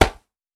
Ball Glove Catch.wav